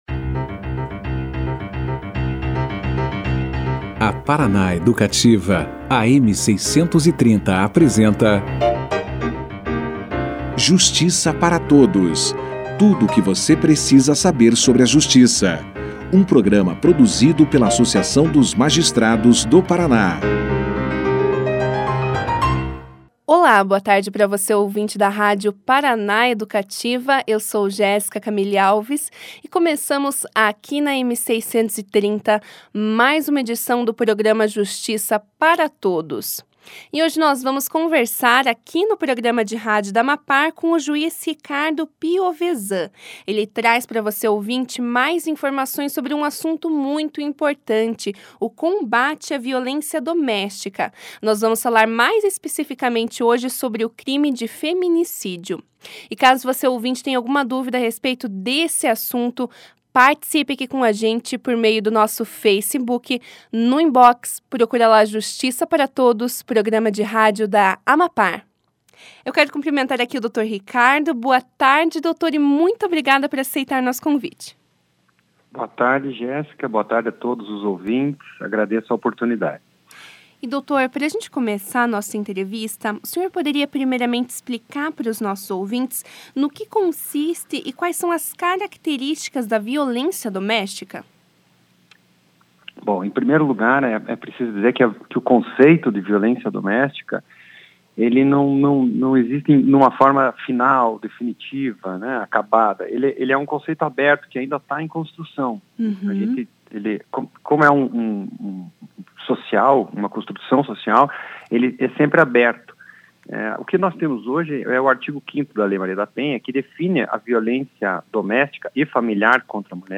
Nesta quarta-feira (29), o juiz Ricardo Piovesan conversou com o Justiça para Todos sobre o crime de feminicídio. Ele explicou primeiramente aos ouvintes da rádio Educativa no que consiste a violência doméstica e o feminicídio, apresentando características desses tipos de violência. Além de explicar a origem do termo feminicídio o magistrado fez uma correlação da cultura patriarcal como disseminadora da violência de gênero.